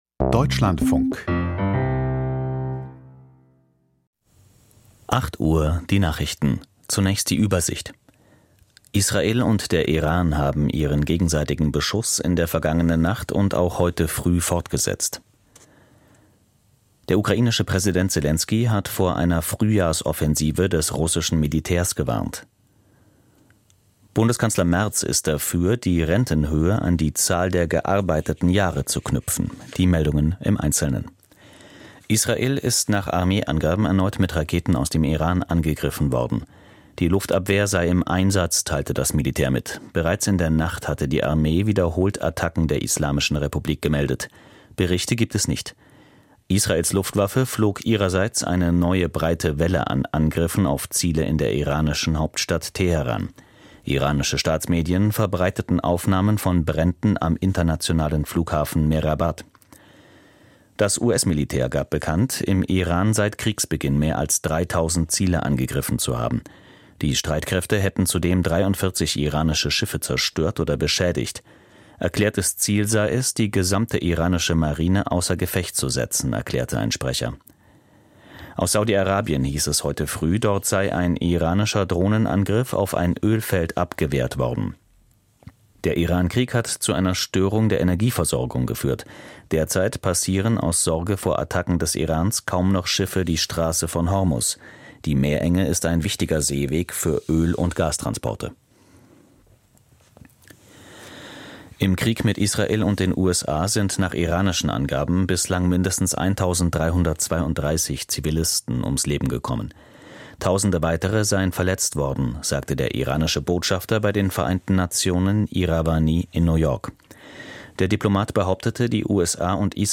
Die Nachrichten vom 07.03.2026, 08:00 Uhr
Aus der Deutschlandfunk-Nachrichtenredaktion.